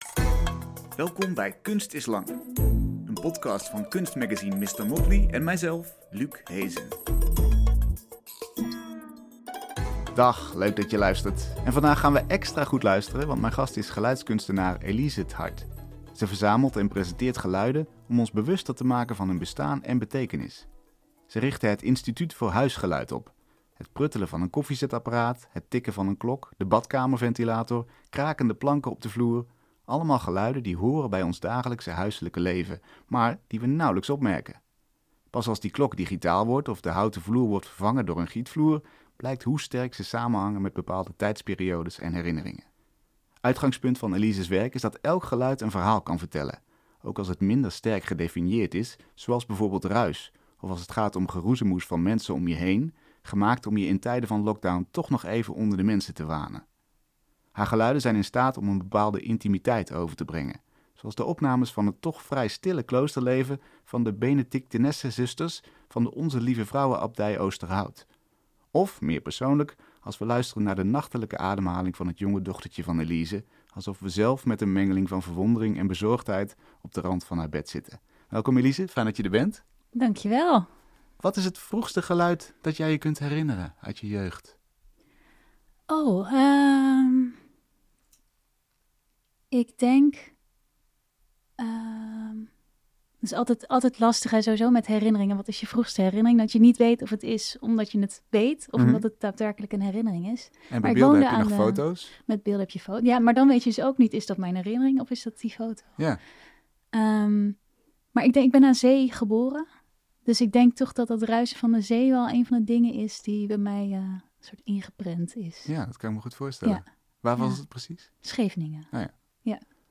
Je hoort elke week een uitgebreid gesprek met een kunstenaar over de belangrijkste thema's van diens oeuvre, inspiratiebronnen en drijfveren.